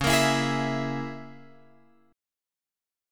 C#m6 chord